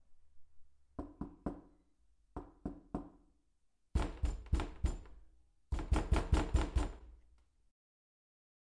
敲门声
描述：木门休闲敲门和硬敲打
Tag: OWI 踩住 砰砰 敲击